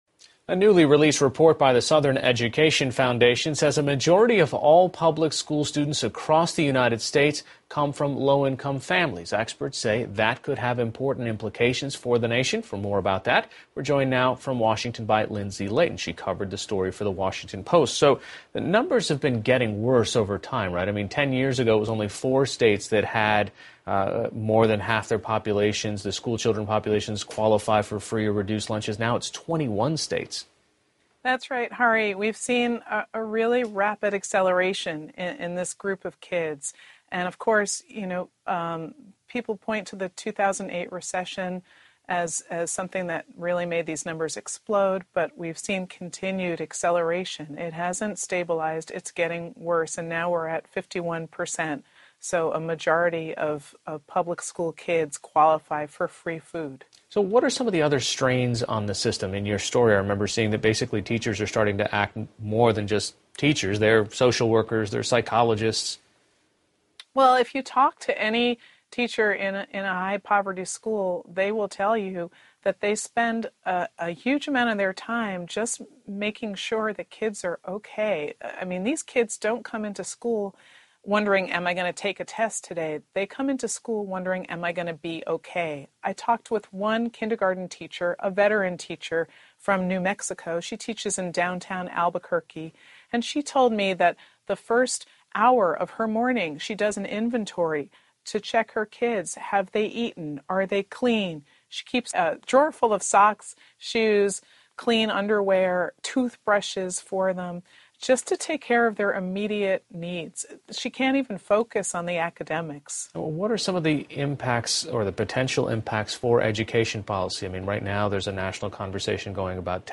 PBS高端访谈:随着更多的孩子家境贫寒,教育系统面临将会更大的挑战 听力文件下载—在线英语听力室